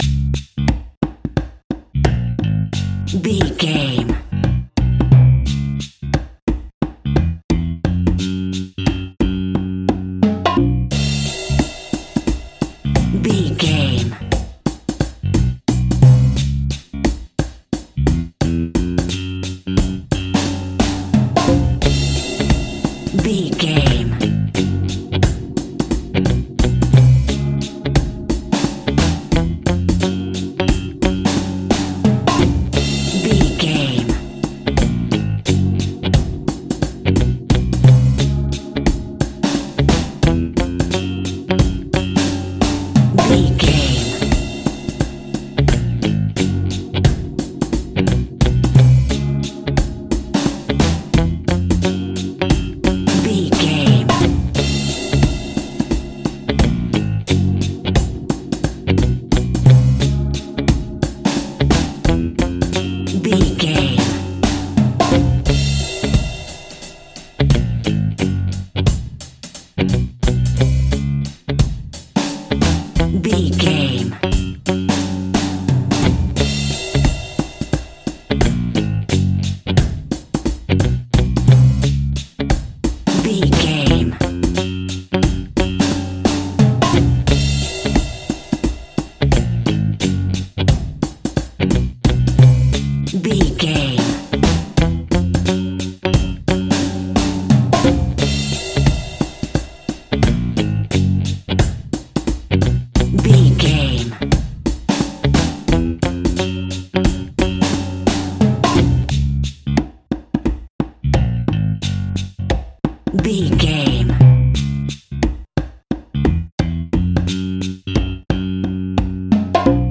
A groovy and funky piece of classic reggae music.
Aeolian/Minor
D♭
laid back
off beat
skank guitar
hammond organ
horns